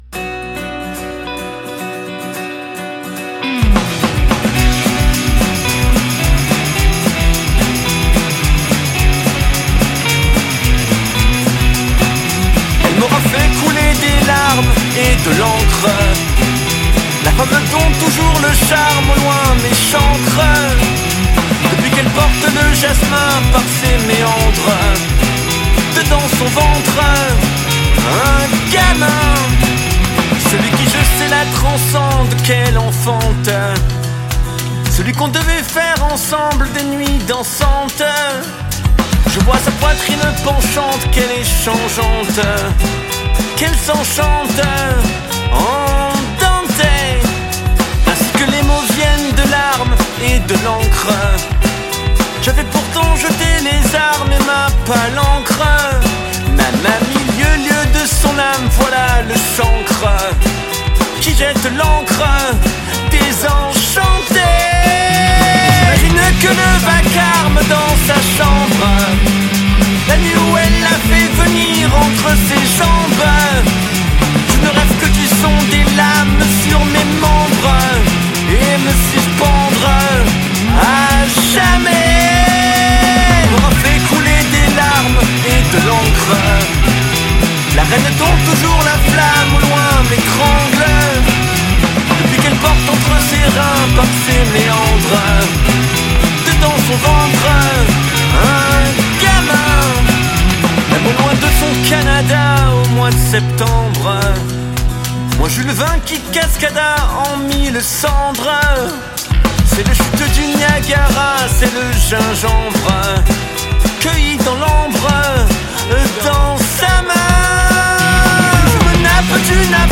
auteur, compositeur, interprète de rock français.